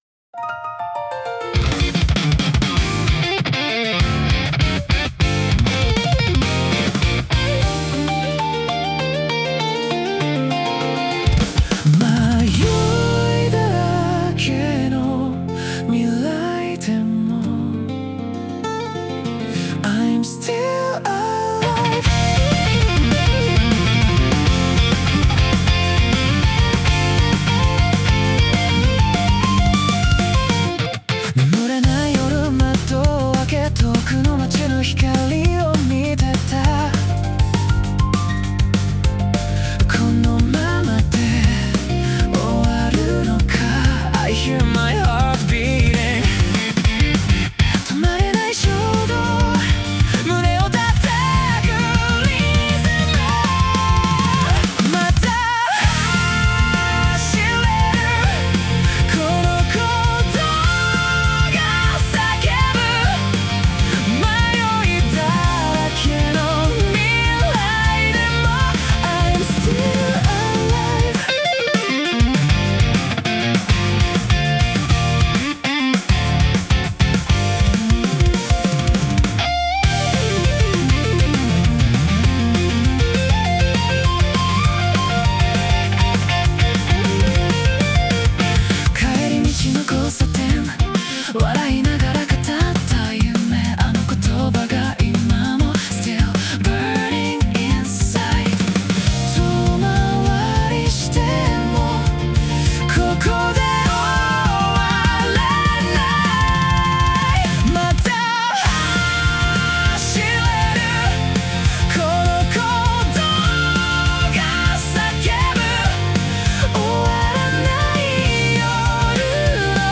イメージ：J-POP,J-ROCK,男性ボーカル